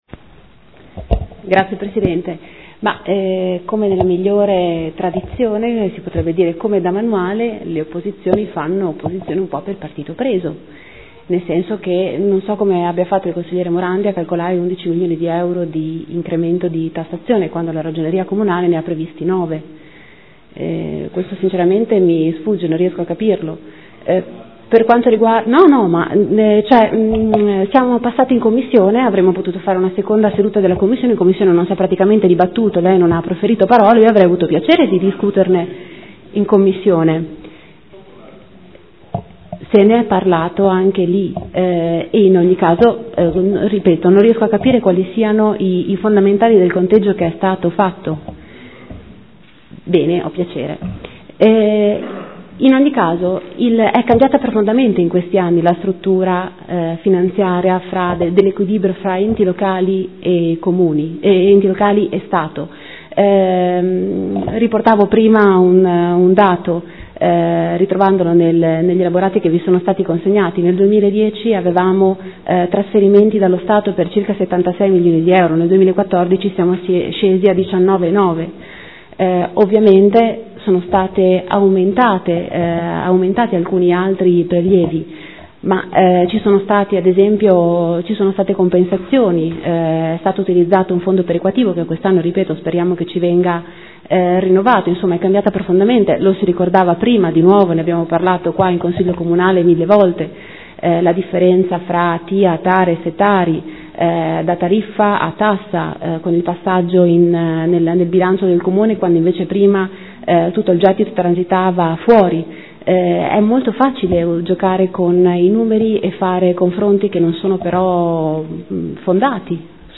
Seduta del 30/04/2015 Replica a dibattito. Rendiconto della gestione del Comune di Modena per l’Esercizio 2014 – Approvazione